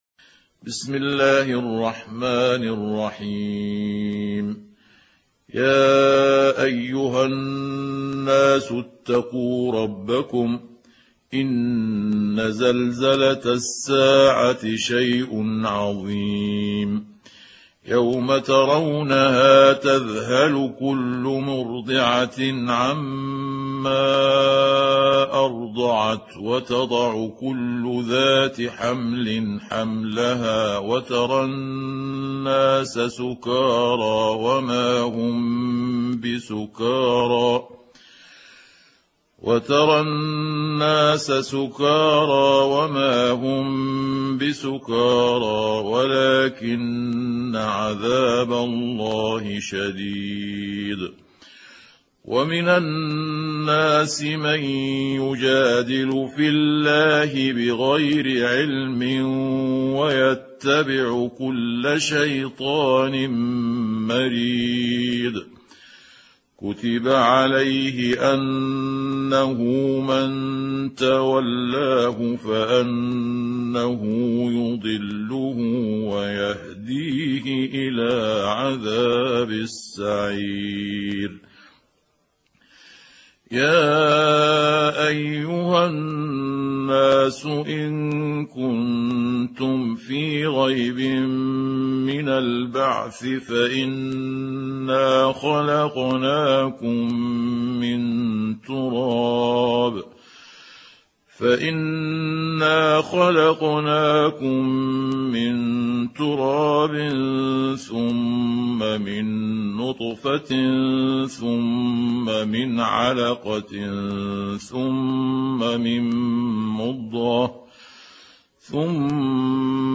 سورة الحج | القارئ محمود عبد الحكم